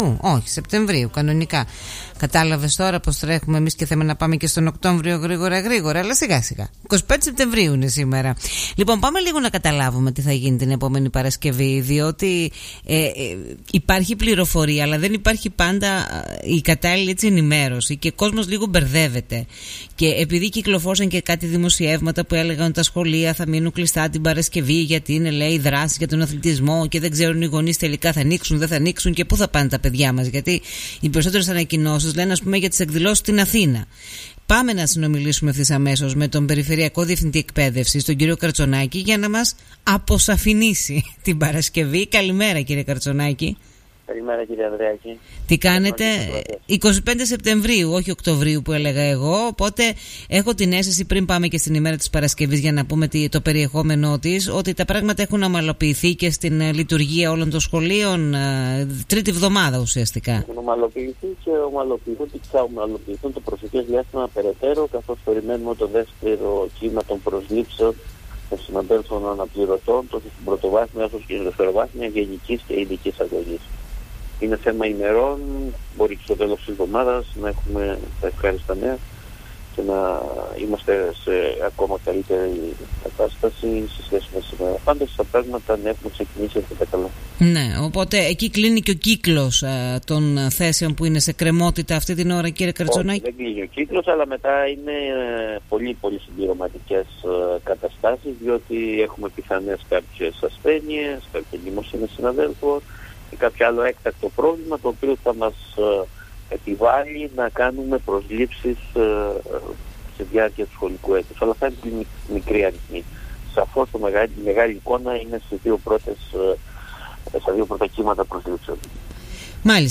Ακούστε εδώ όσα είπε ο Περιφερειακός Διευθυντής της Εκπαίδευσης Κρήτης Μανώλης Καρτσωνάκης στον Politca89.8: